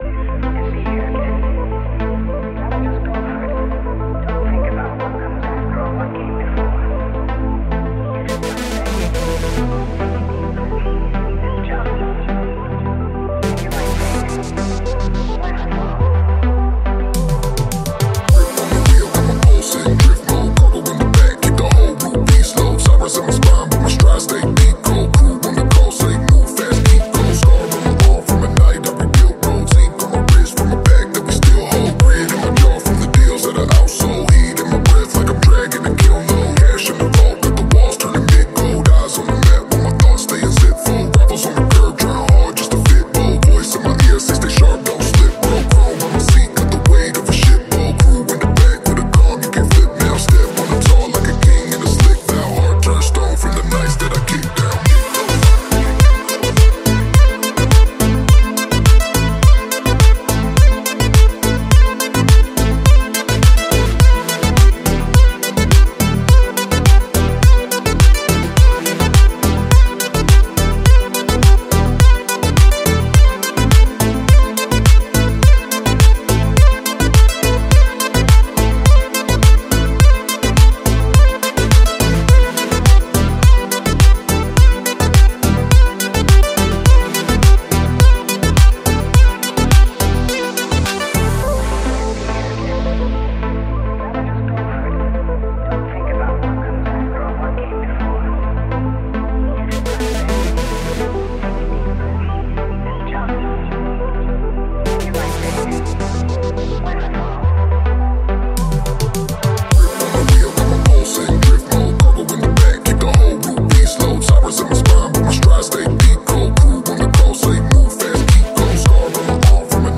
با ریتم‌های کوبنده و اتمسفر سینمایی
Deep، Epic، Dark و Cinematic